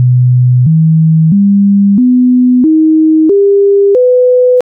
octaafmiddenfrequenties
In onderstaand voorbeeld hoor je een opeenvolging van de volgende octaafmiddenfrequenties:
125, 250 Hz, 500 Hz, 1000 Hz en 2000 Hz: